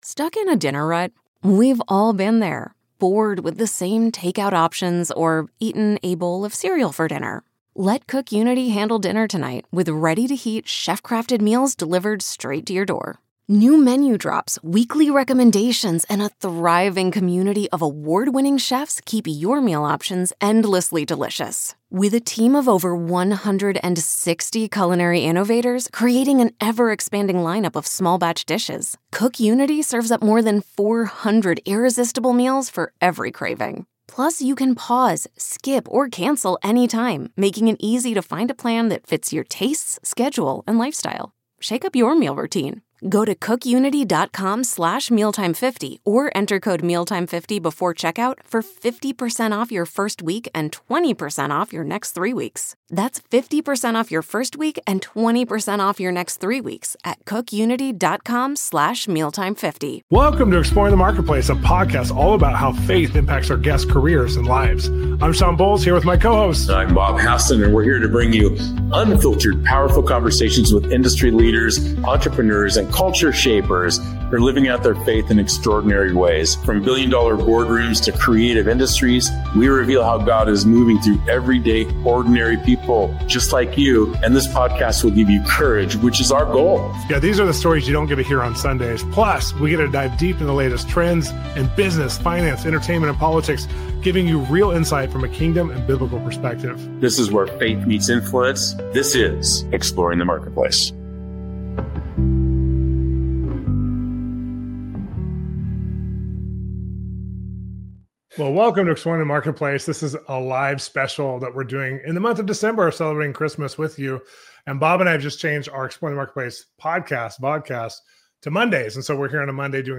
Come join the conversation!